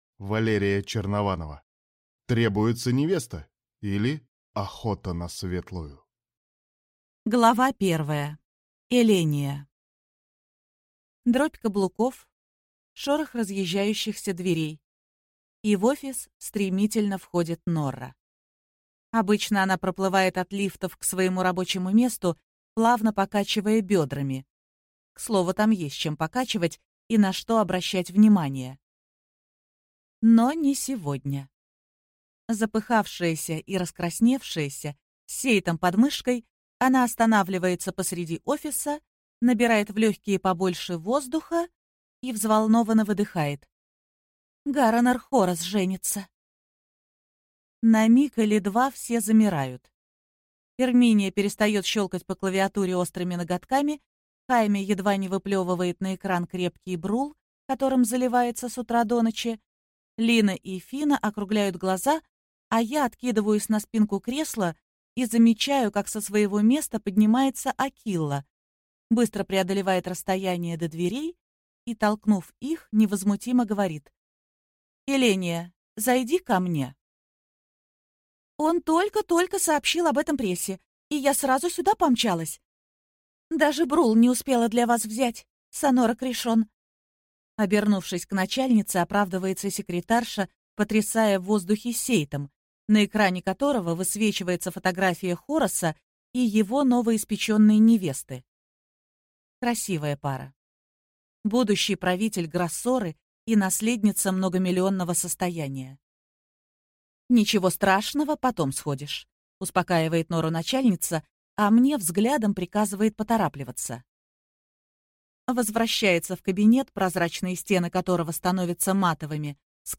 Аудиокнига Требуется невеста, или Охота на Светлую | Библиотека аудиокниг